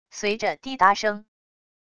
随着滴答声wav音频